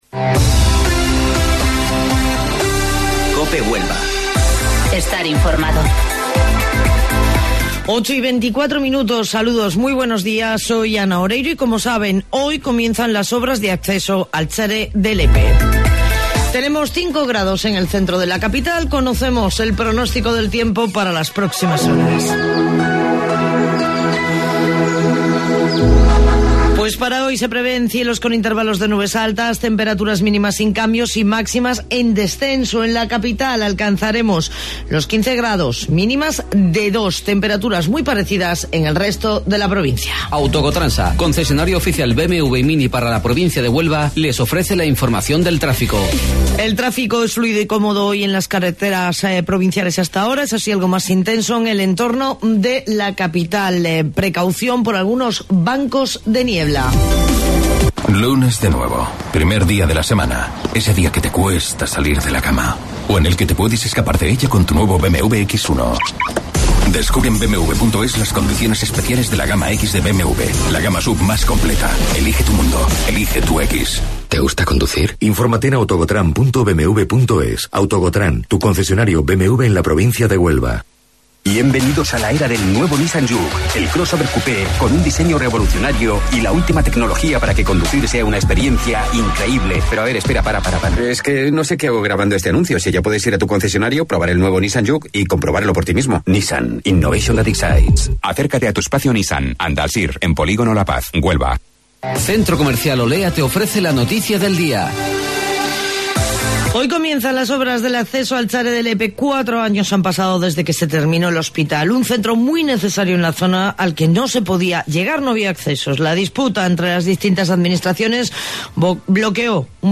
AUDIO: Informativo Local 08:25 del 13 Enero